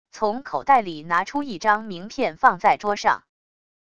从口袋里拿出一张名片放在桌上wav音频